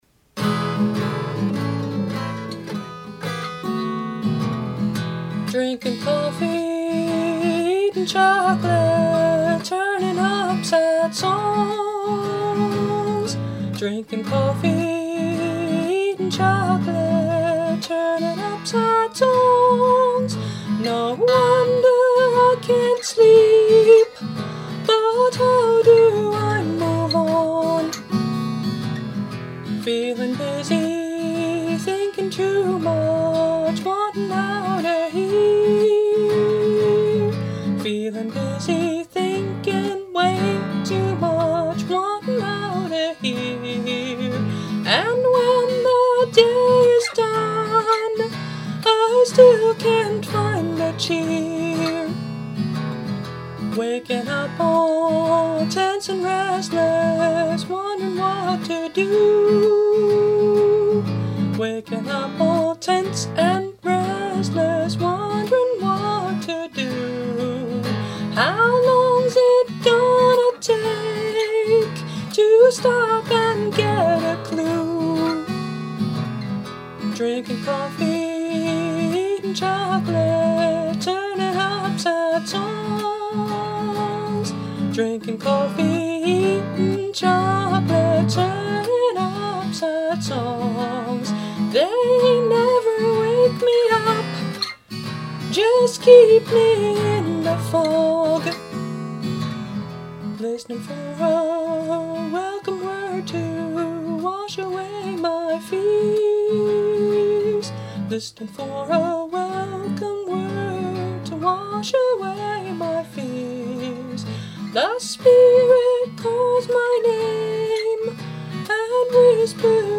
Instrument: Tempo – Seagull Excursion Folk Acoustic Guitar